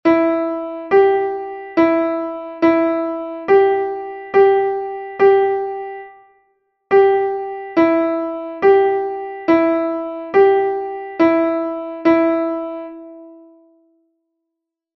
Melodic reading practice
exercise 2 G and E